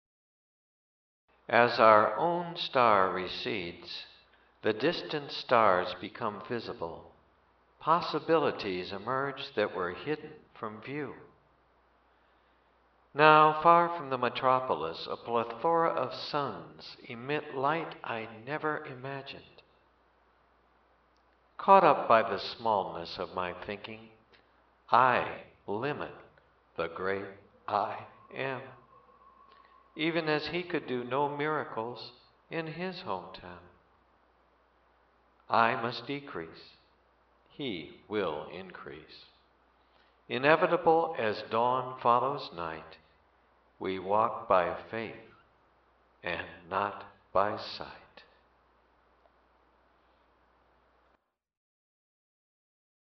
AUDIO READINGS